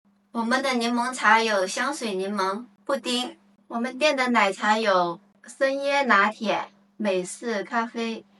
Högkonverterande AI-försäljningsröst
Driv mer intäkter med en övertygande, högenergi AI-röst designad för reklamfilmer, livestreaming och marknadsföringsinnehåll.
Övertygande berättarröst
Livestream-skript